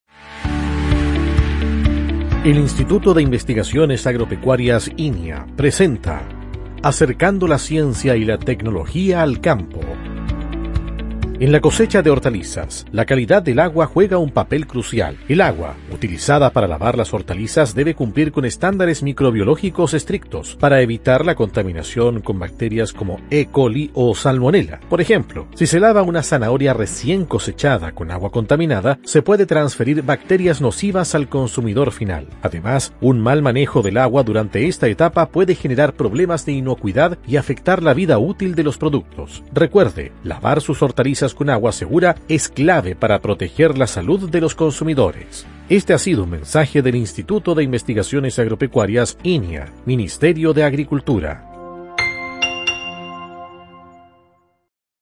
Cápsula Radial INIA Remehue